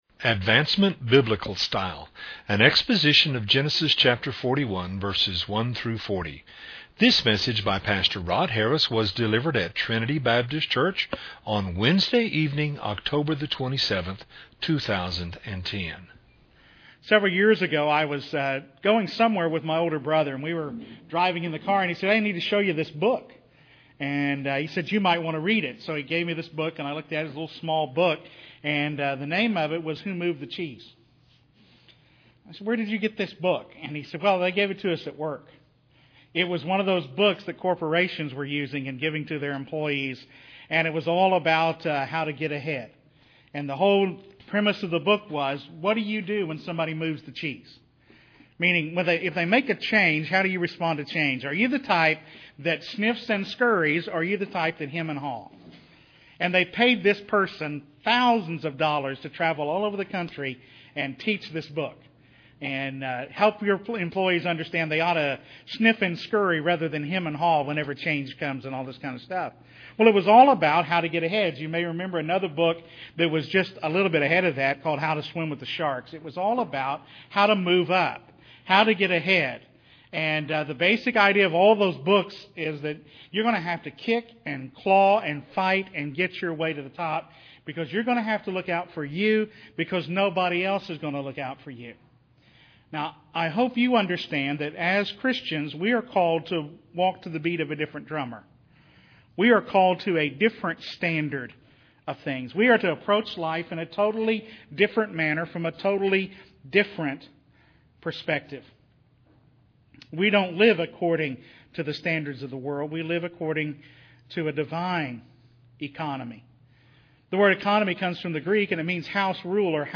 was delivered at Trinity Baptist Church on Wednesday evening, October 27, 2010.